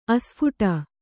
♪ asphuṭa